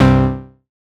Synth Stab 06 (C).wav